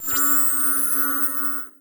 forcefield1.ogg